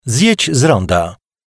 Male 30-50 lat
Sounds excellent as a narrator, in telecom systems and e-learning courses.